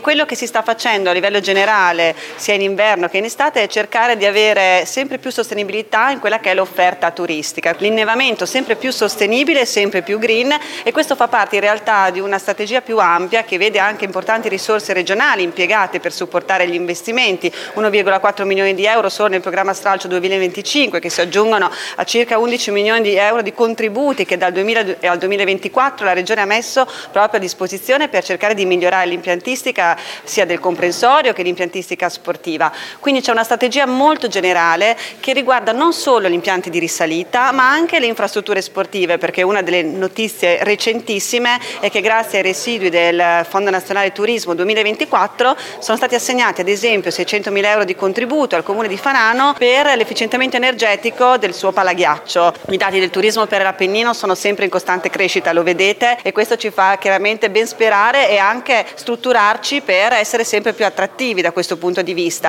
L’assessore regionale al turismo Roberta Frisoni sottolinea gli investimenti fatti: